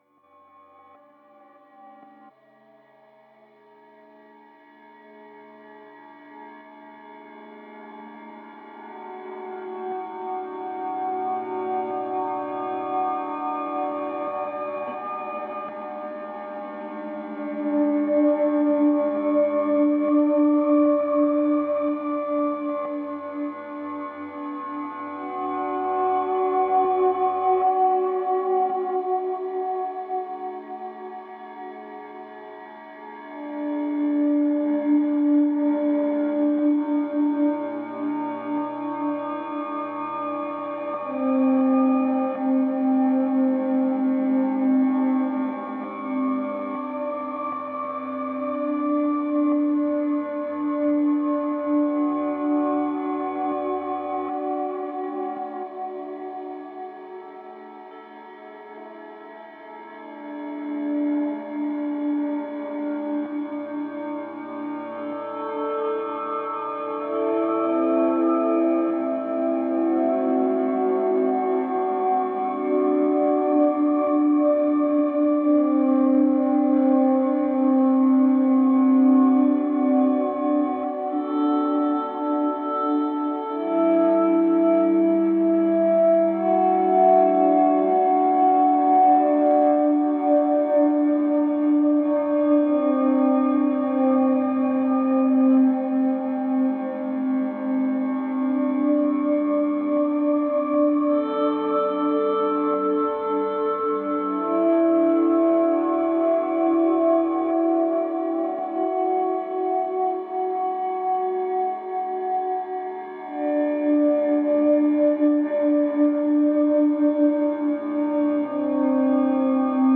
records ambient sound with the goal of environmental enhancement through sound. His performances are site-specific, spontaneous compositions influenced by both the physical characteristics of the space they are performed in and the occupants of the space.